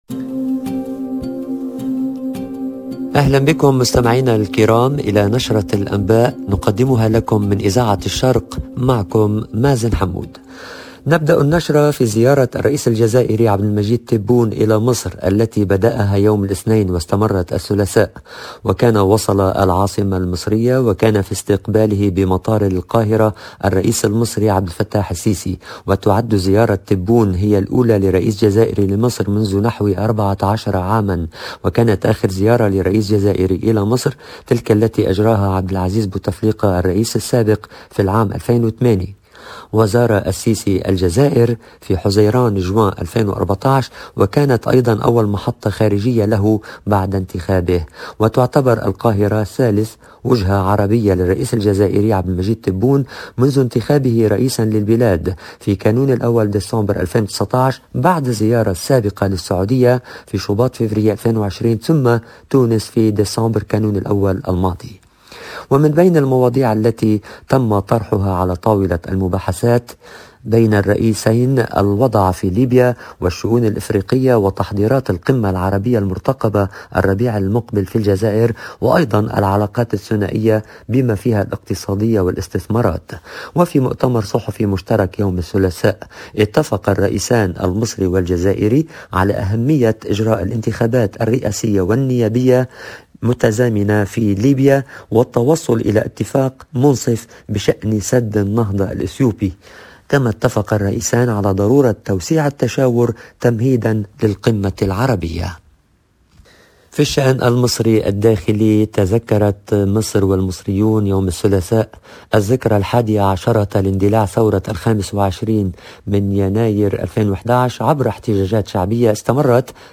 LE JOURNAL DU SOIR EN LANGUE ARABE DU 25/01/21